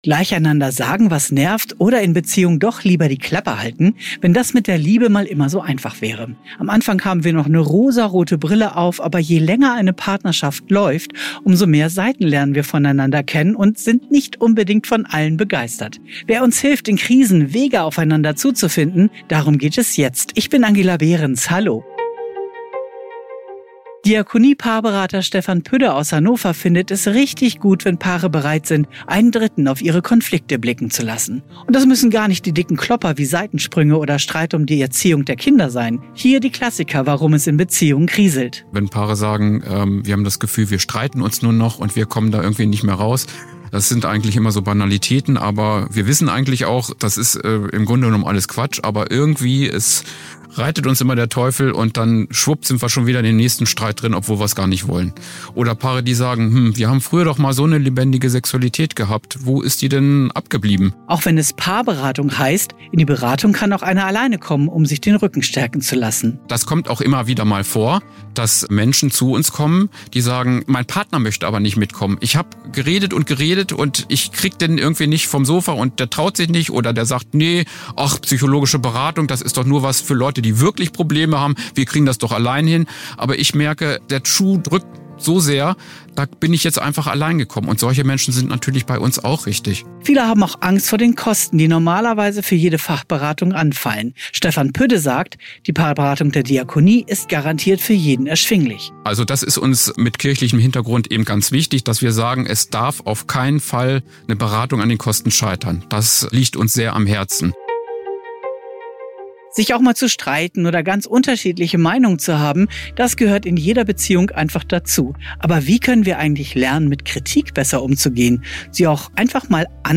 „ffn - Die Kirche - Hilfe interaktiv“ ist eine wöchentliche Beratungssendung bei radio ffn in Zusammenarbeit mit der Diakonie. Menschen in Not aus Niedersachsen berichten im Gespräch auf sehr persönliche Weise, wie sie in scheinbar ausweglose Situationen geraten sind. Zusammen mit Beraterinnen und Beratern der Diakonie werden am konkreten Fall Auswege aus der Krise beschrieben.